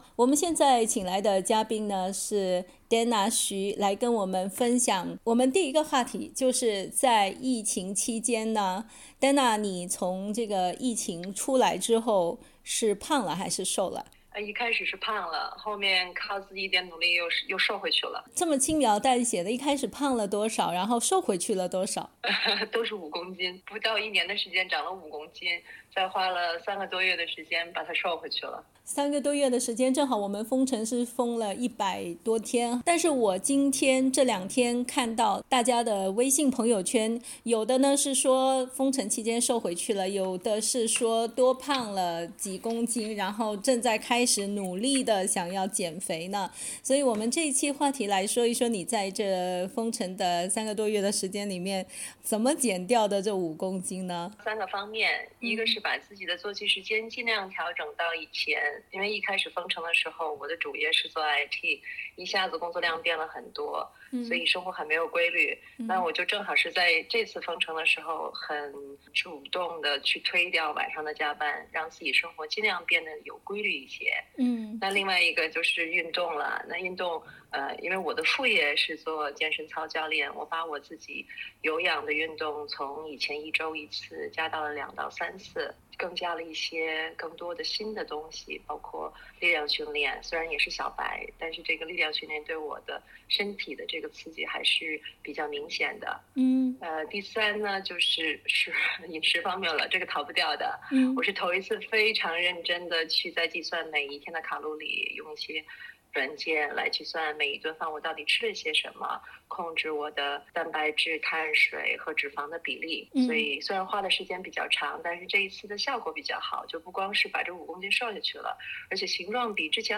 同時，她還從飲食、運動方面雙管齊下，終於在封城結束之後，成功恢复此前正常的體重。 (點擊上圖收聽寀訪） 澳大利亞人必鬚與他人保持至少1.5米的社交距離，請查看您所在州或領地的最新社交限制措施。